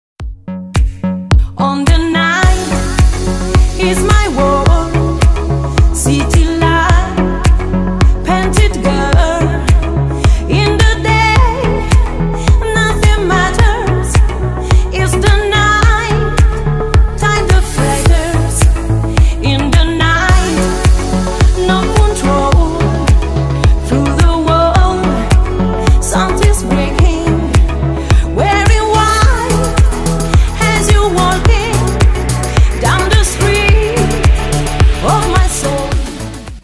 Танцевальные рингтоны
Клубные рингтоны